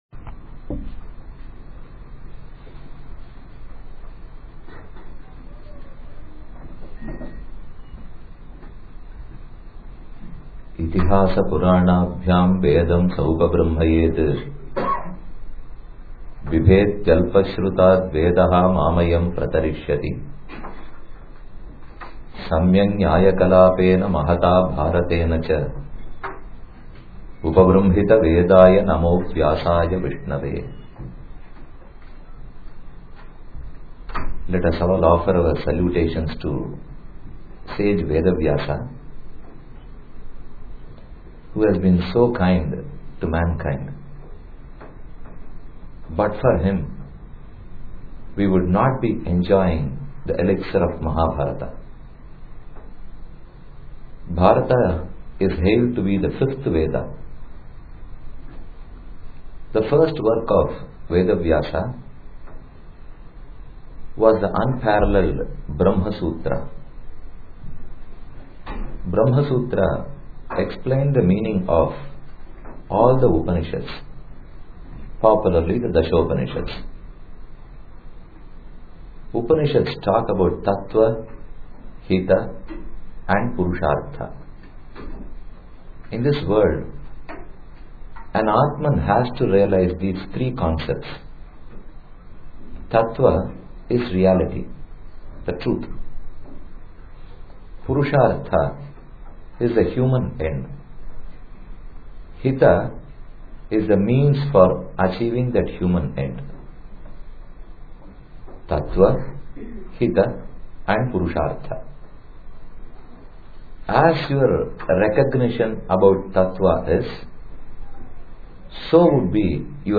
The Hindu Temple of New Hampshire, Nashua, NH, held an Akhanda Parayana (Uninterrupted Recitation) of the Sri Vishnu Sahasranama Stotra over the Thanksgiving weekend with the intent of seeking the grace of the almighty for world peace.
Devotees of the Hindu Temple of New Hampshire chanted in chorus, the 1000 auspicious names of Lord Sri Vishnu, set to a melodious rhythm that was popularized in the rendering by the legendary singer Smt.